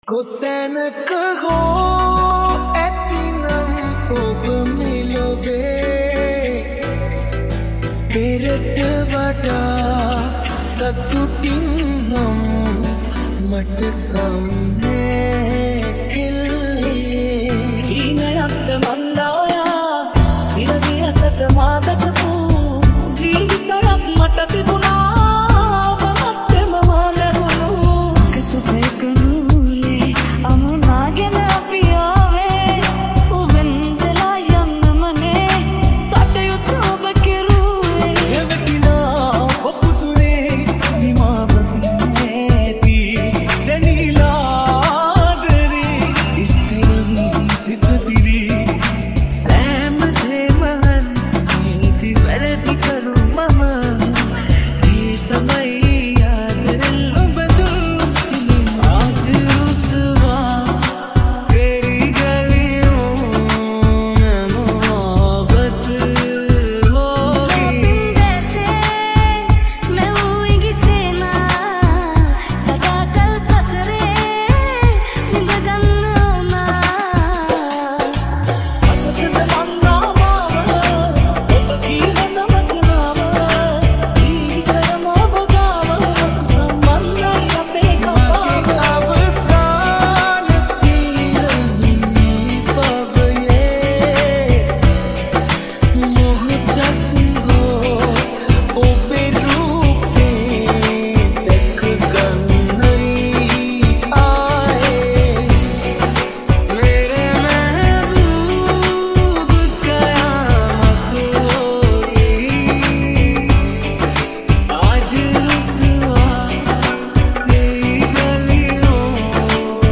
High quality Sri Lankan remix MP3 (6).